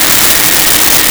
Shop Vac Loop
Shop Vac Loop.wav